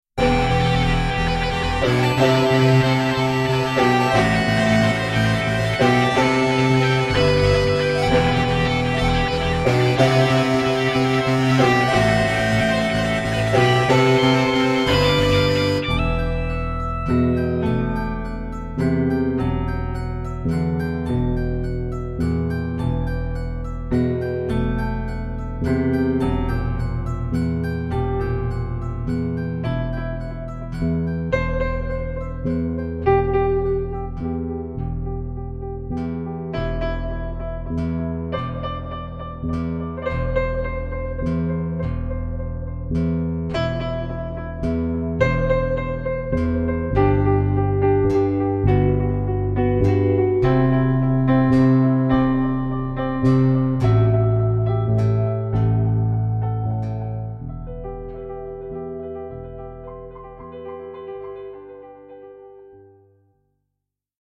RECALLS LEAD MELODY ONE FINAL TIME